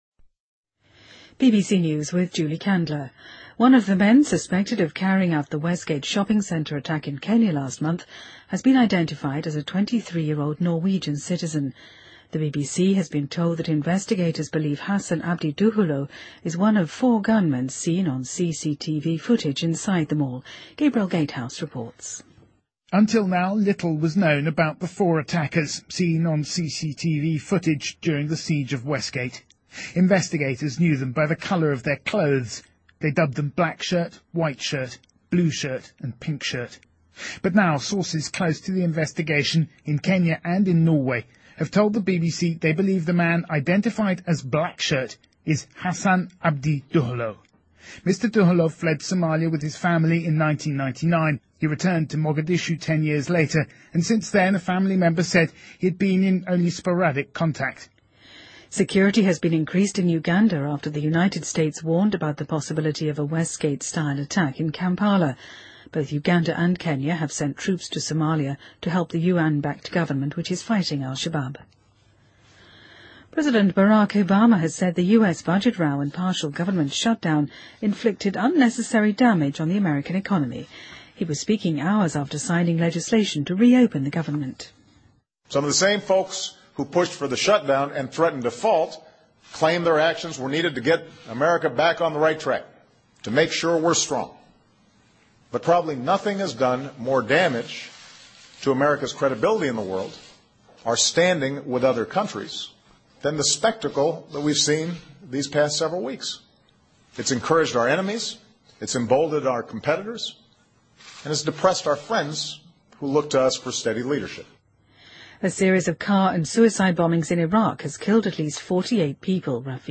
BBC news,美国警告说坎帕拉可能会发生西门商场那样的袭击案